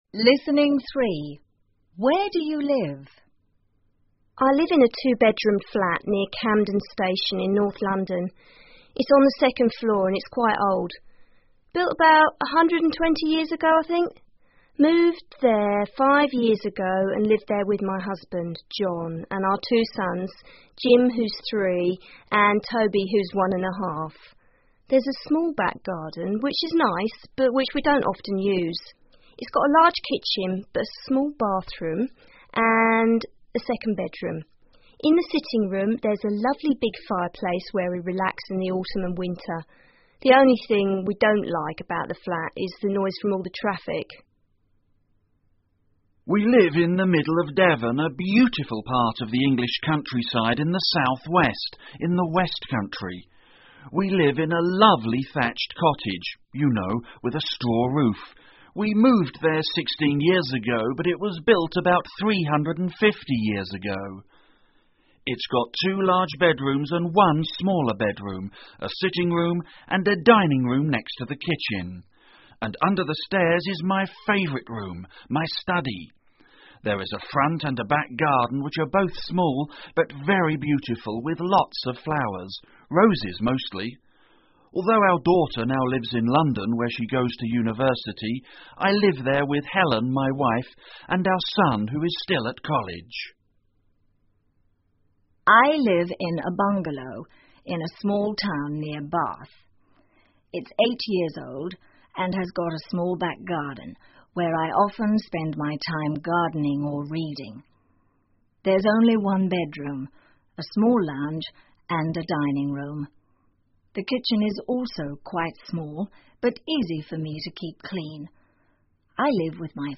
2. An elderly woman living alone
3. A housewife living in the city
4. A middle-aged married man